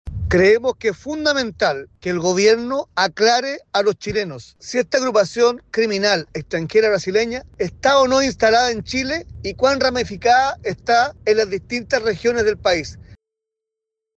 Desde la UDI, el jefe de bancada, Henry Leal, emplazó al Gobierno a informar qué tan ramificada estaría esta organización transnacional en el país.